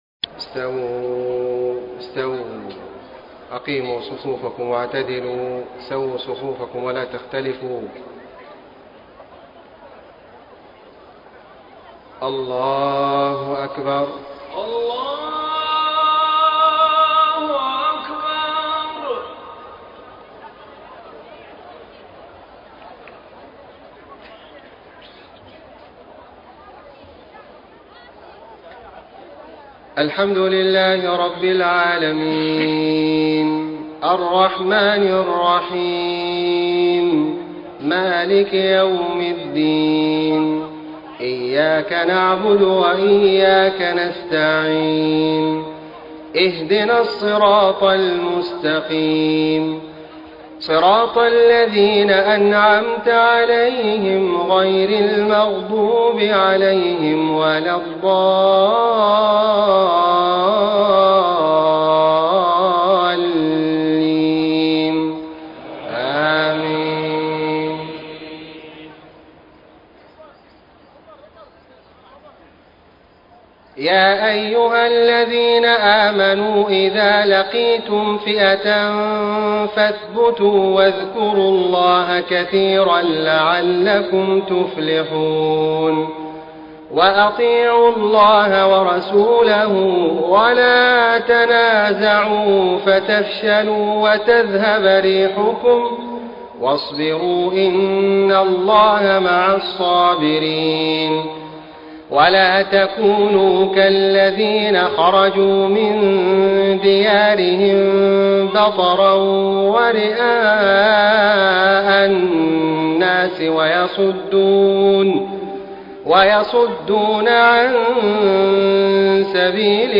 صلاة العشاء 4 - 4 - 1434هـ من سورة الأنفال > 1434 🕋 > الفروض - تلاوات الحرمين